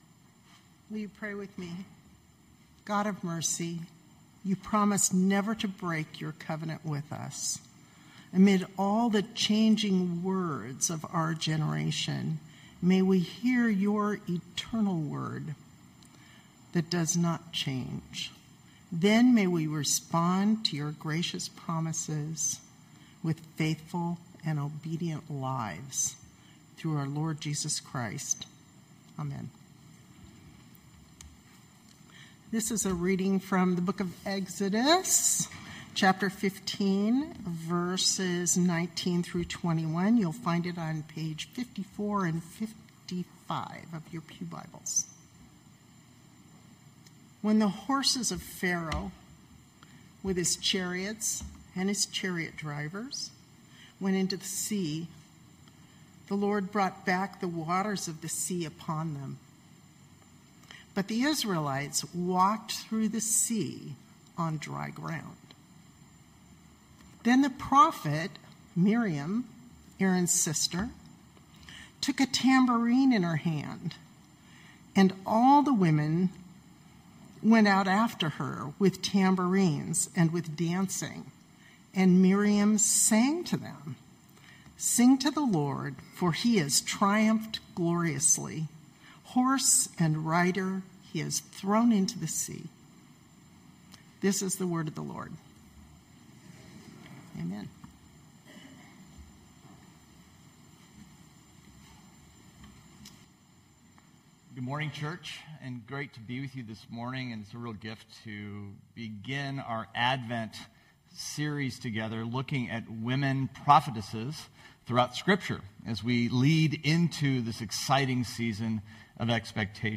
Knox Pasadena Sermons Snowballs and Electric Trains Nov 30 2025 | 00:22:57 1x 00:00 / 00:22:57 Subscribe Share Spotify RSS Feed Share Link Embed